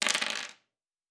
Fantasy Interface Sounds
Dice Multiple 5.wav